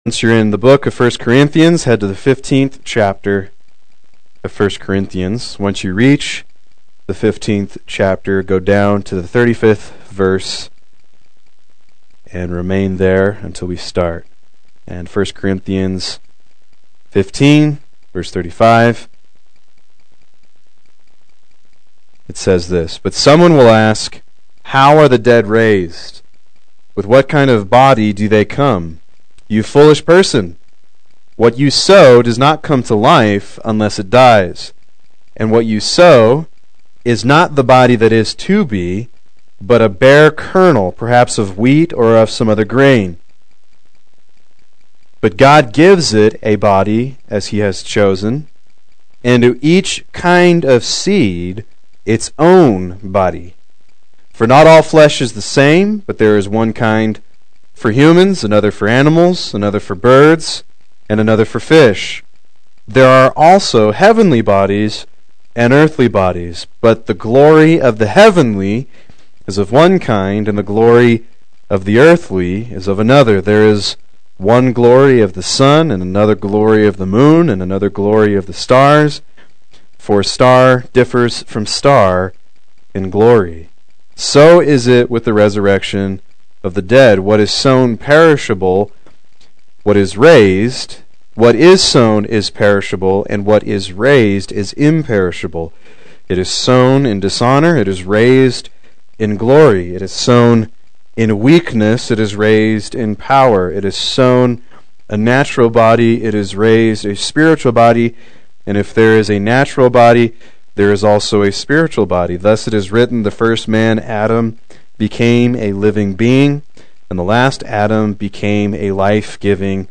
Proclaim Youth Ministry - 12/03/16
Play Sermon Get HCF Teaching Automatically.